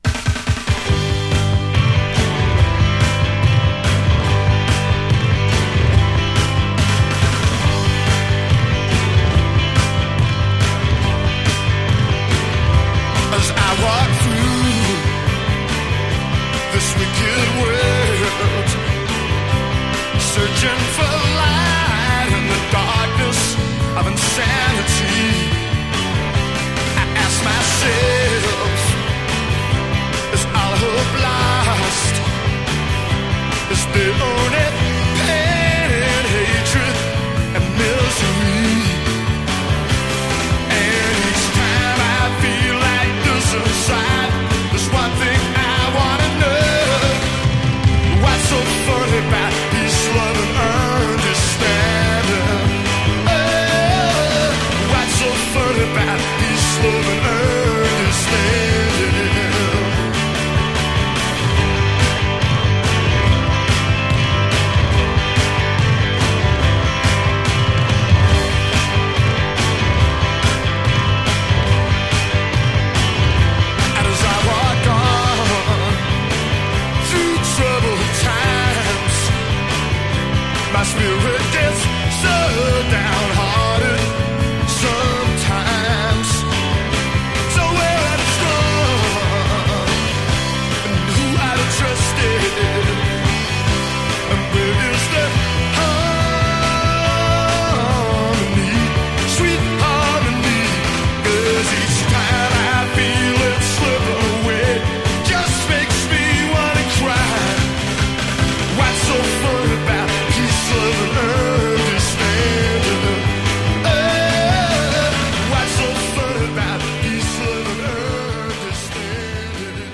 Genre: Modern Rock